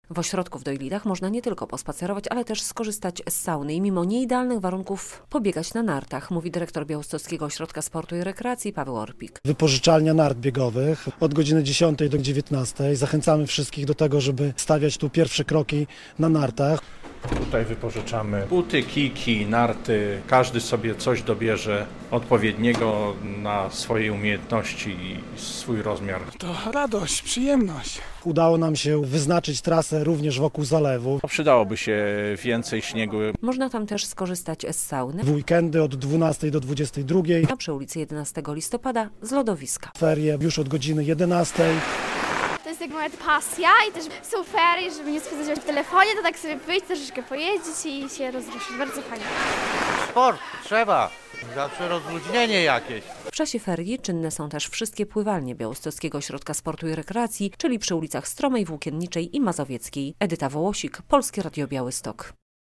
Jak aktywnie spędzić feryjny weekend w Białymstoku? - relacja